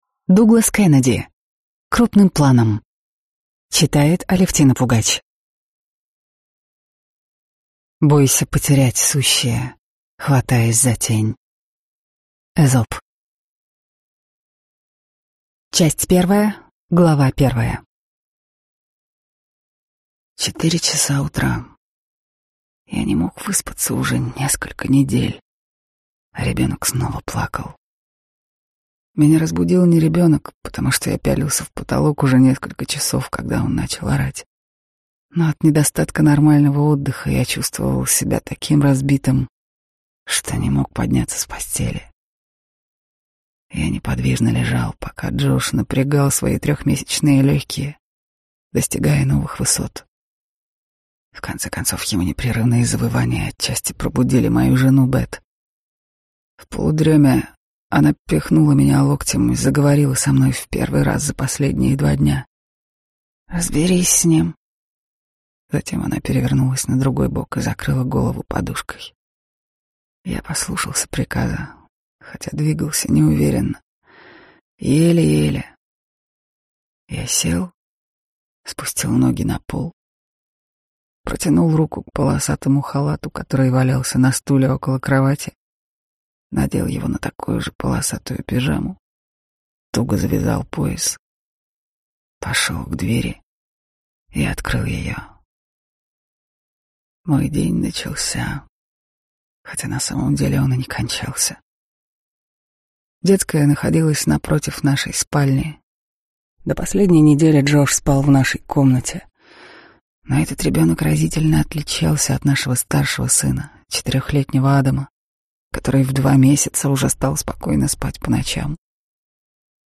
Aудиокнига Крупным планом